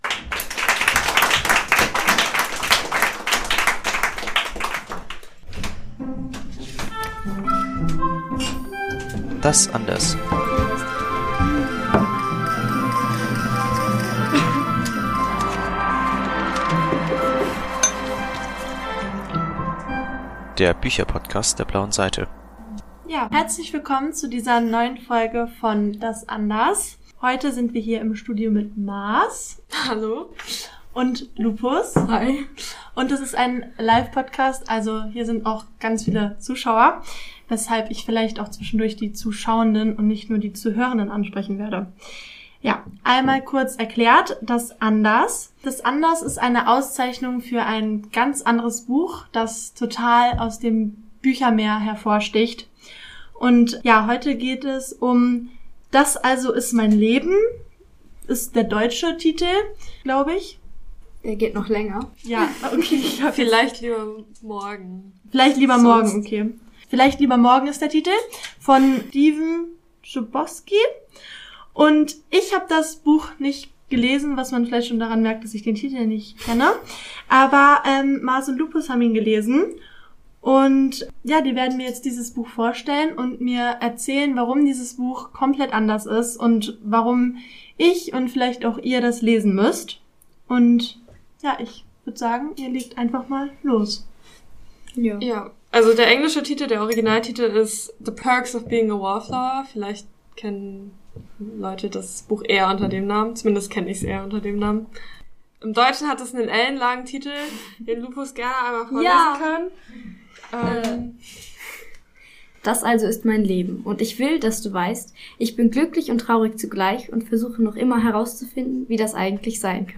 Live-Aufnahme vom Podcast-Marathon "Podcast und Plätzchen" im Dezember 2024 Mehr